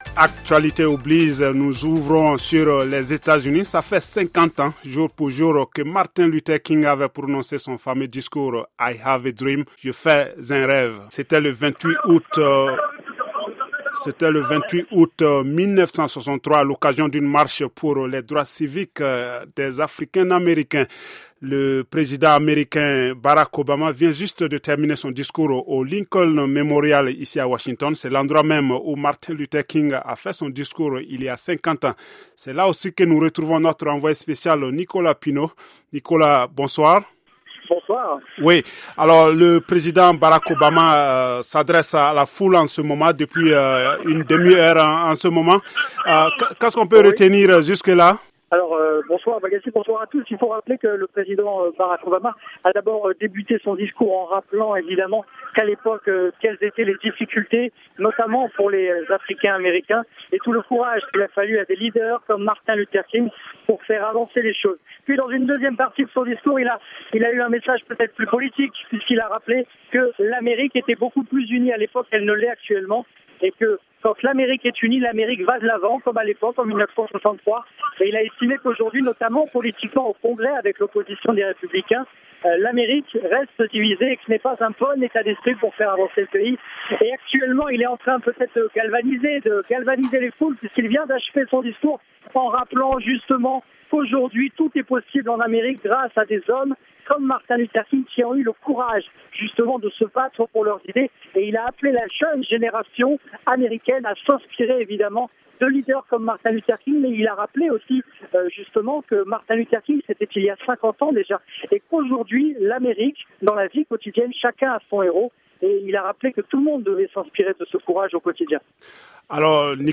MLK- Reportage et Debat sur le 50e anniversaire de la Marche sur Washington